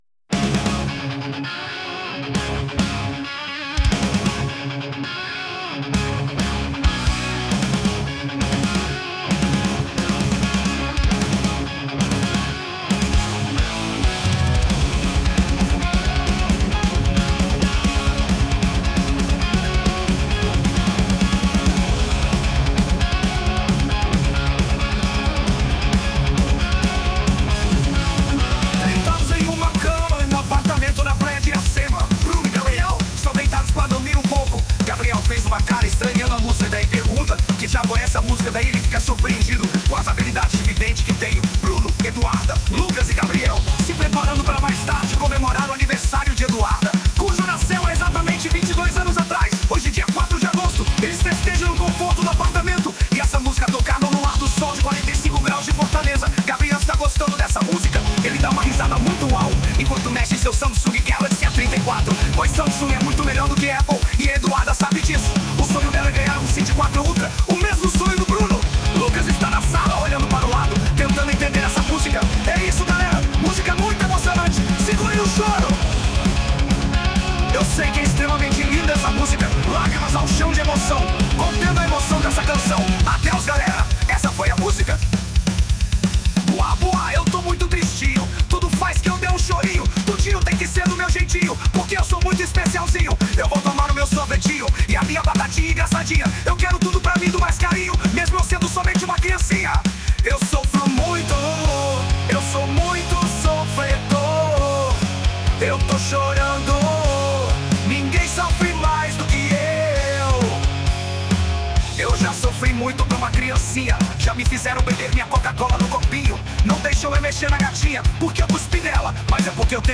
Versão Metal 1